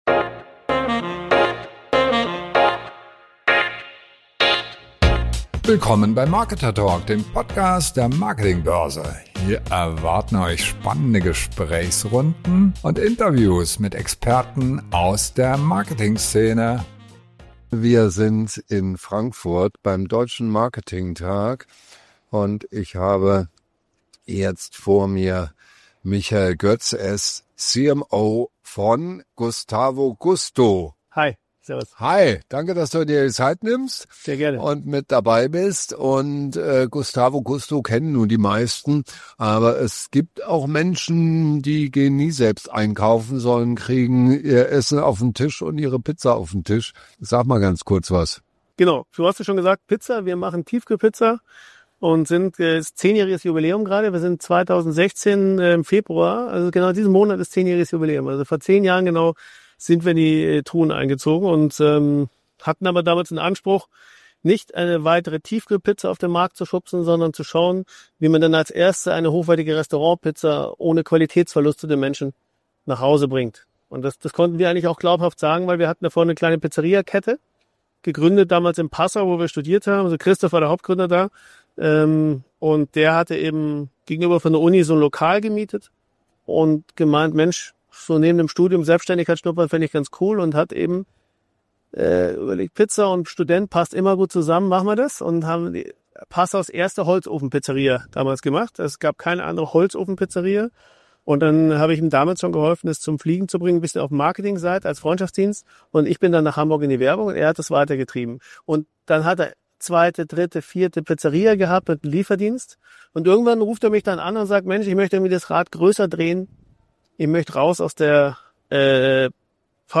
Ein Gespräch über radikal neues Denken, Glück, das man selbst macht, und warum eine weiße Pizza-Schachtel mehr bewirkt als teure TV-Kampagnen.